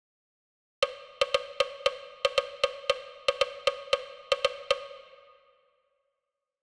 Hemiola Figure (simultaneous, 2/4)